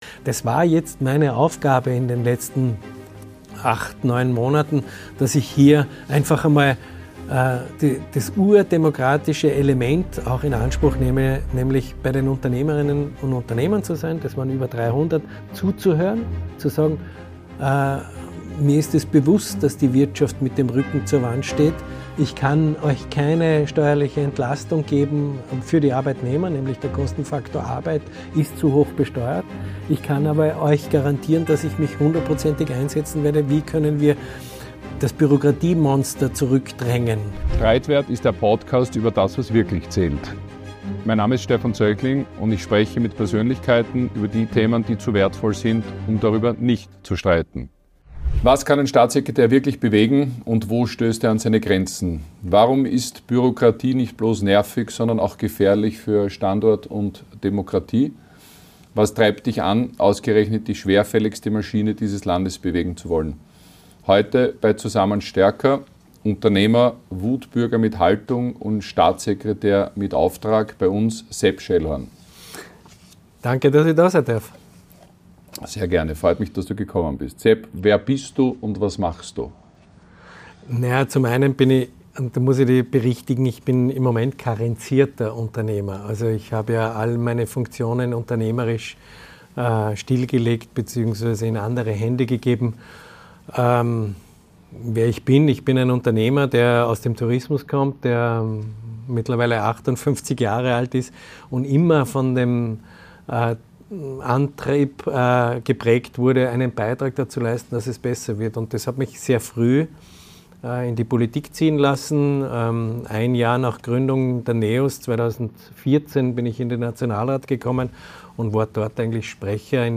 Ein Gespräch darüber, warum es mehr Unternehmer:innen in der Politik braucht und seine Ansätze, Österreich zu entbürokratisieren.